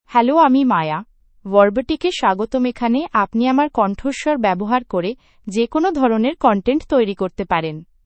FemaleBengali (India)
Maya — Female Bengali AI voice
Voice sample
Female
Maya delivers clear pronunciation with authentic India Bengali intonation, making your content sound professionally produced.